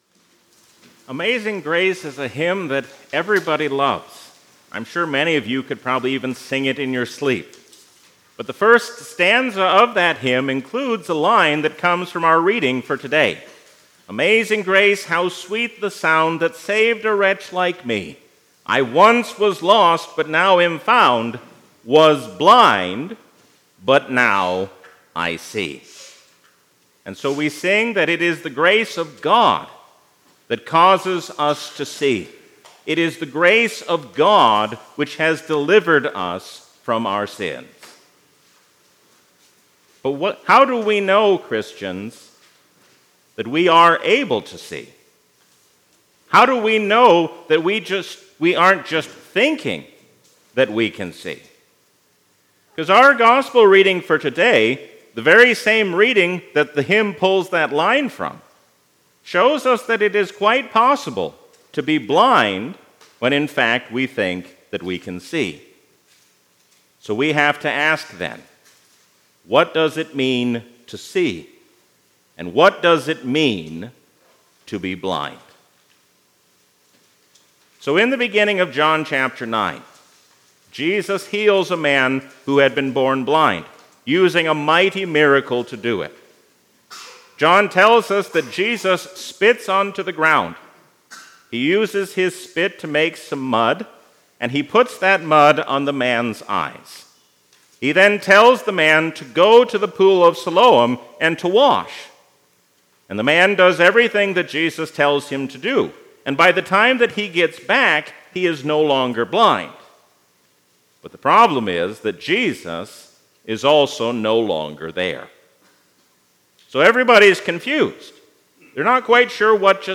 A sermon from the season "Trinity 2023." When we see ourselves rightly, then we will be able to see Jesus rightly too.